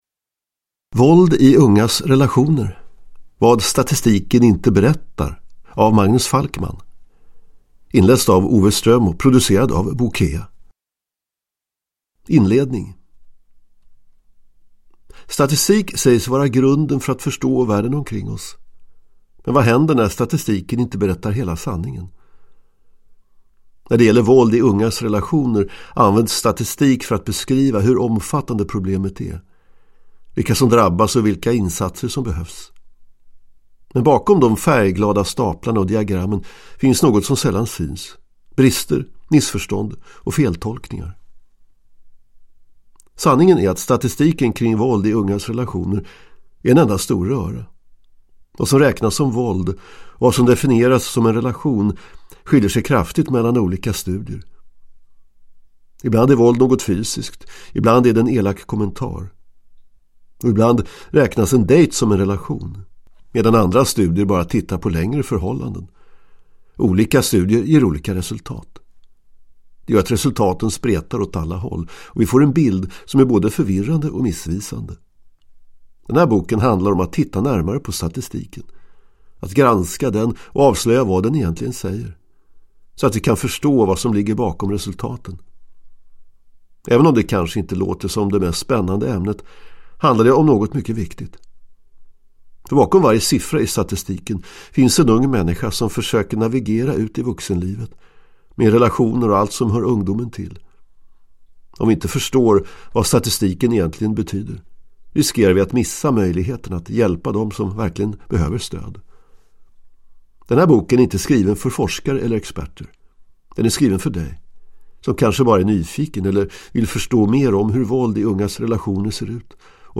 Våld i ungas relationer : vad statistiken inte berättar – Ljudbok